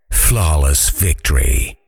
Voiceover
flawless_victory.ogg